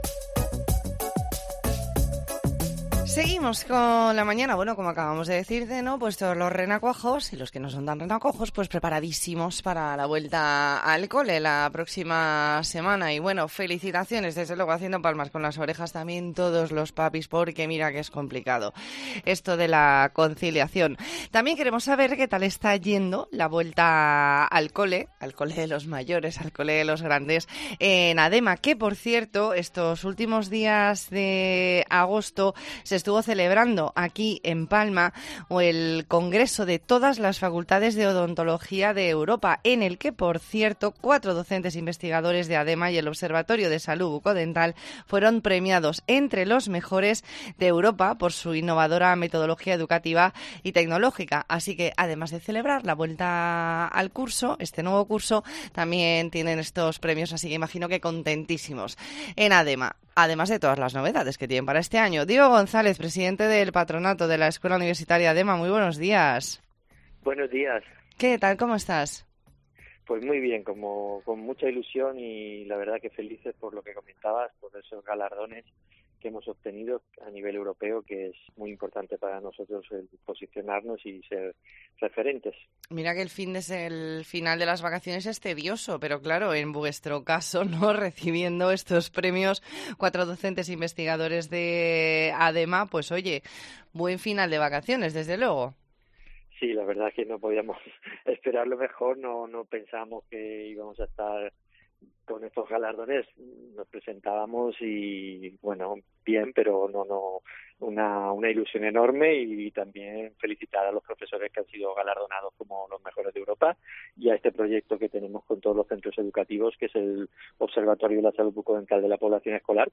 ntrevista en La Mañana en COPE Más Mallorca, jueves 8 de septiembre de 2022.